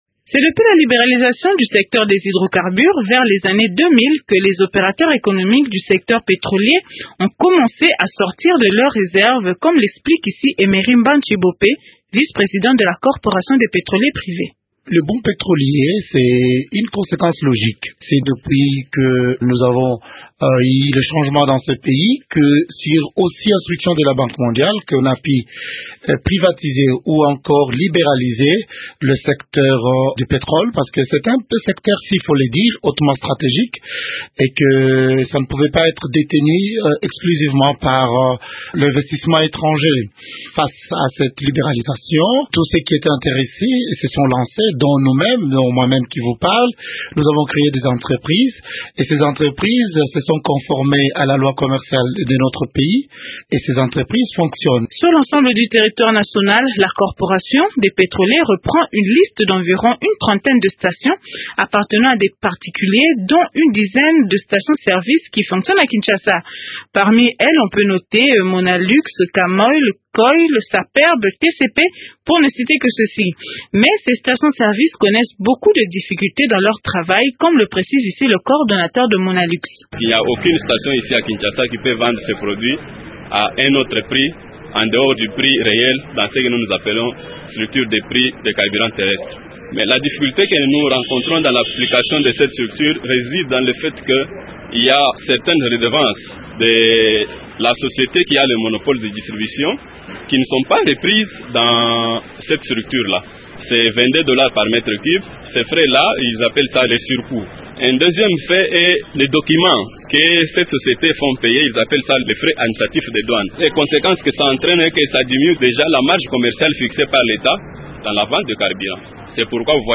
Radio Okapi s’est intéressé au circuit de distribution de carburant dans ce secteur à Kinshasa et vous propose ce reportage.